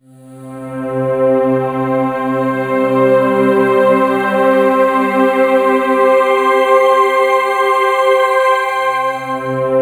FILMSYN C3.wav